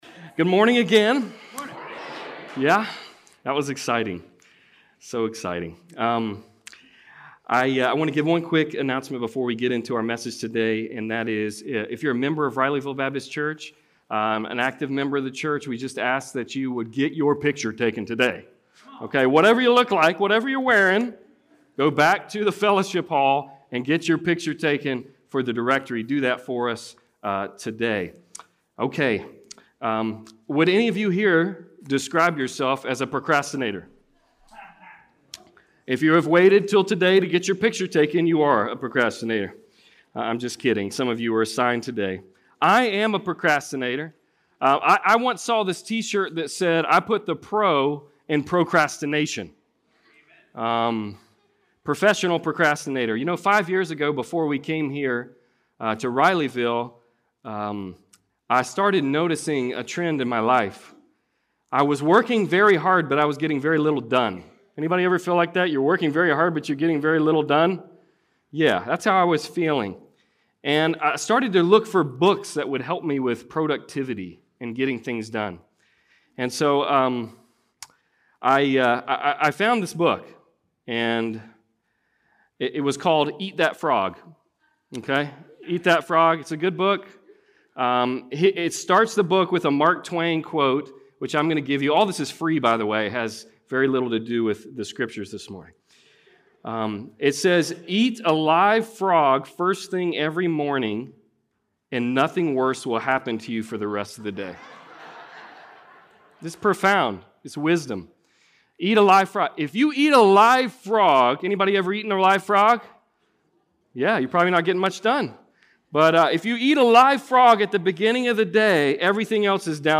Passage: Mark 6:14-29 Service Type: Sunday Service